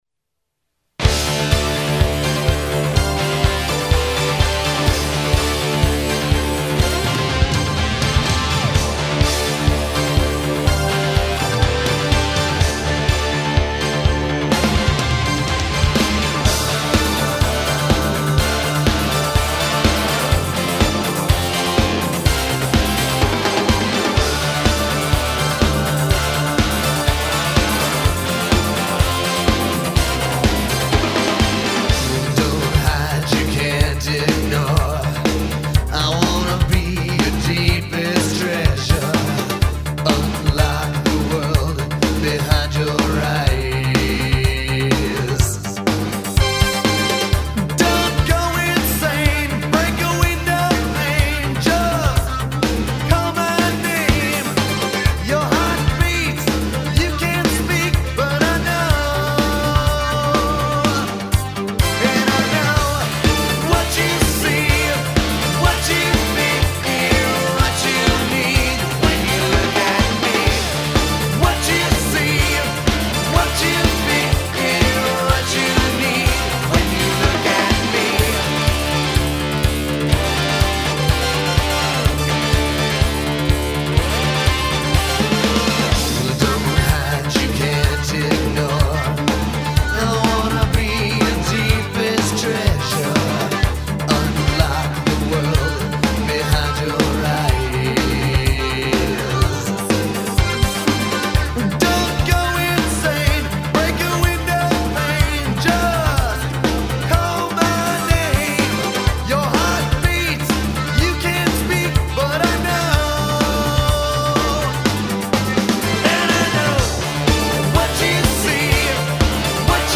This one is him in full "buttrock" mode